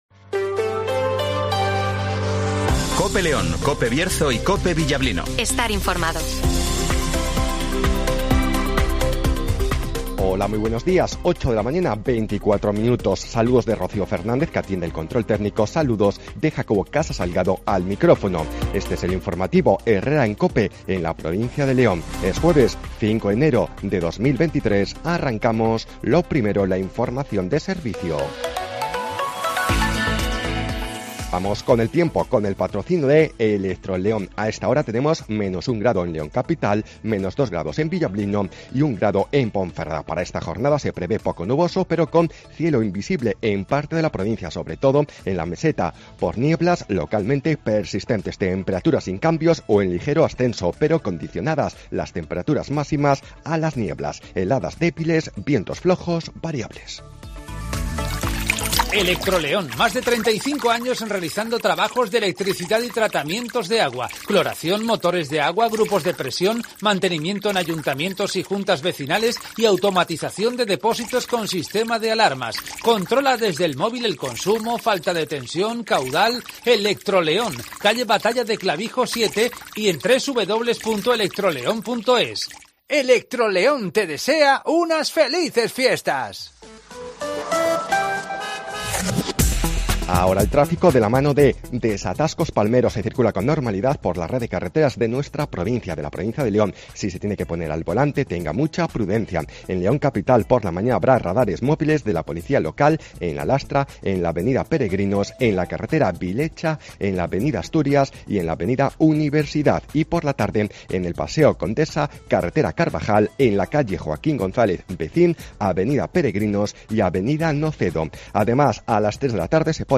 INFORMATIVOS
Repaso a la actualidad informativa de la provincia de León. Escucha aquí las noticias de nuestra provincia con las voces de los protagonistas.